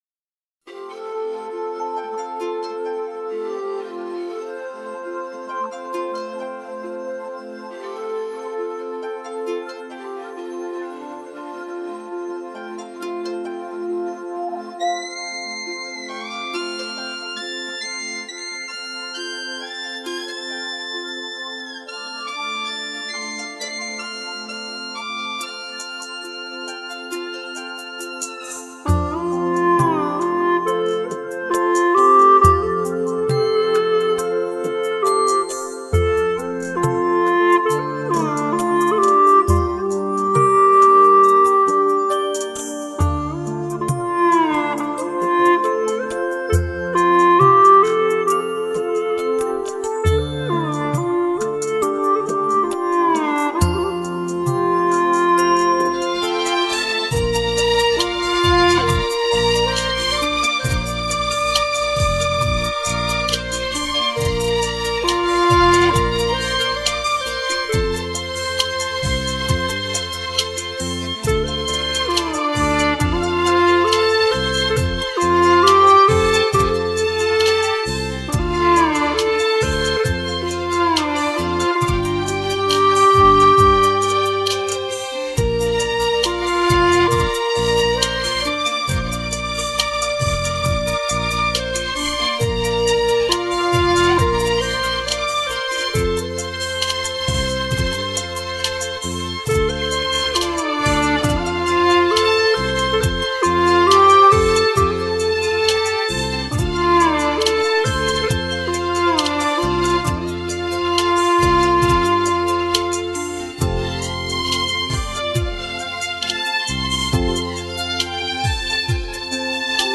调式 : F 曲类 : 独奏